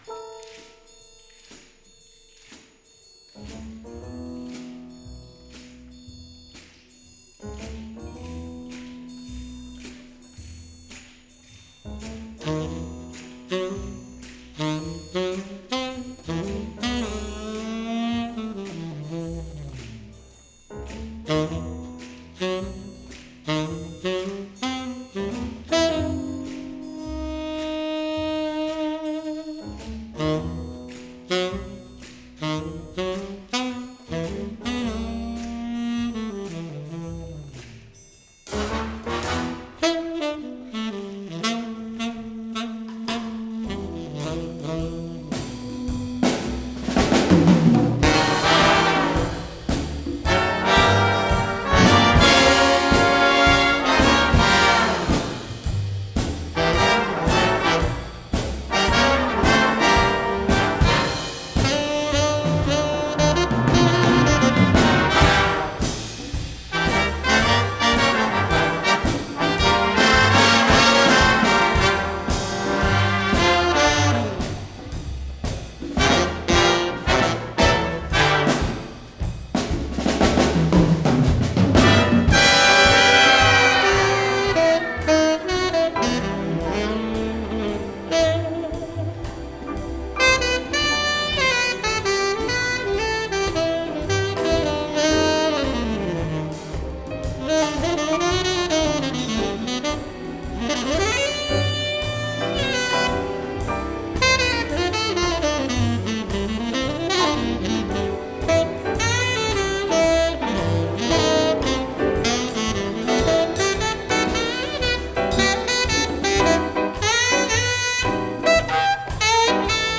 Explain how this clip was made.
Live-Performance